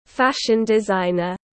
Nhà thiết kế thời trang tiếng anh gọi là fashion designer, phiên âm tiếng anh đọc là /ˈfæʃn dɪzaɪnər/.
Fashion designer /ˈfæʃn dɪzaɪnər/